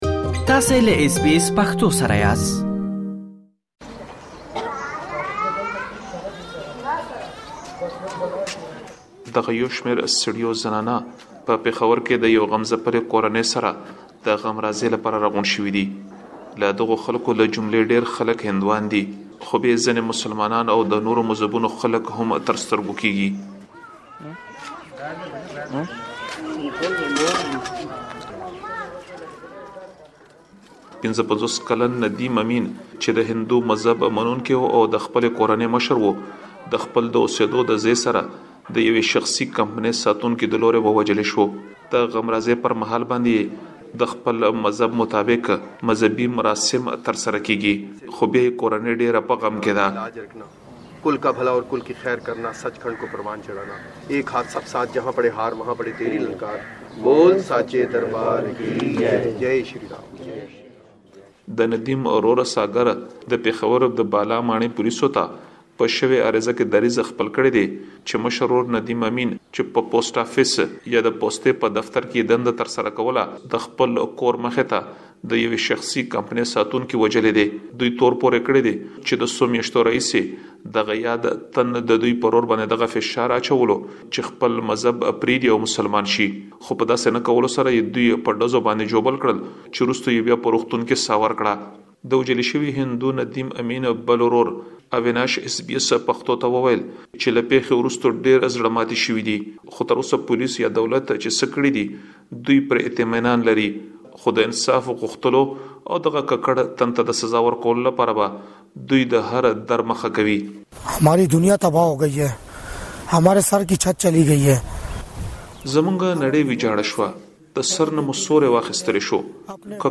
په دې اړه لا ډېر معلومات په رپوټ کې اورېدلی شئ.